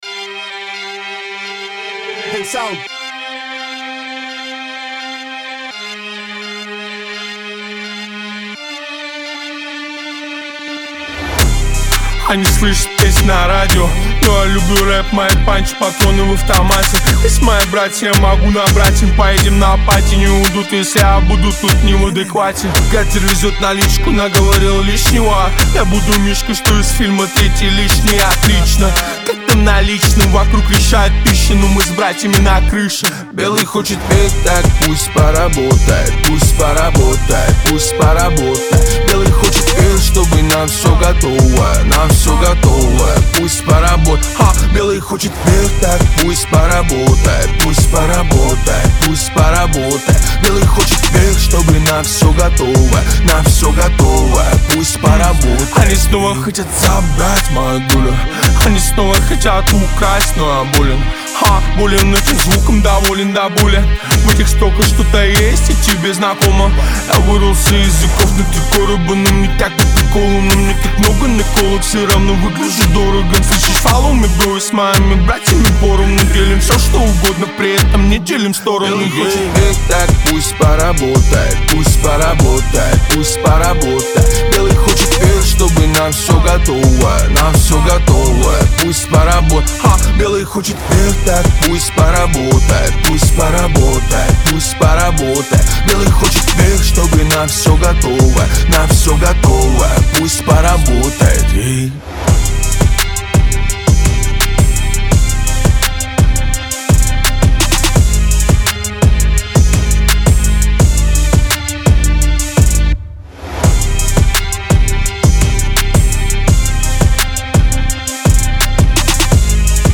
Трек размещён в разделе Русские песни / Рэп и хип-хоп.